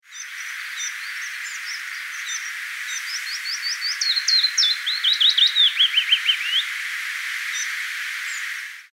begin tjif en dan verder fitis, of het is